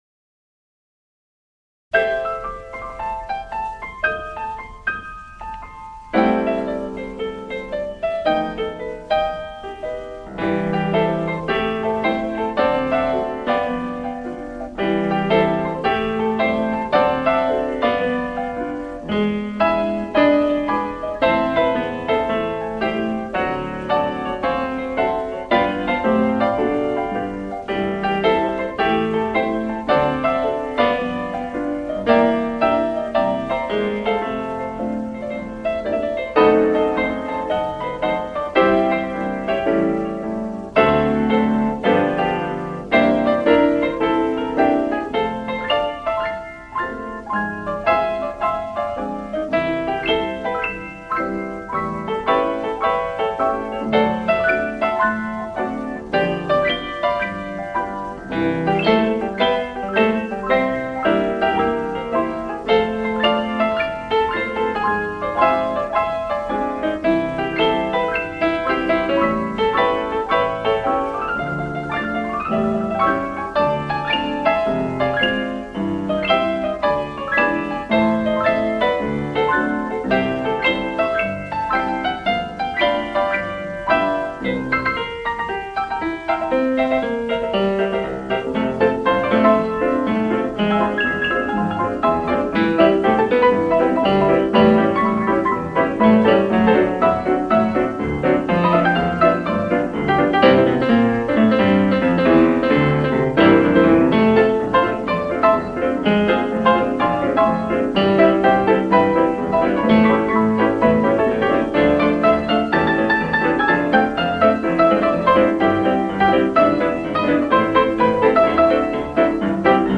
Jazz After Dark spans the gamut, from roots in boogie-woogie, blues, and ragtime through traditional and straight-ahead jazz, soul jazz, bossa nova, and more.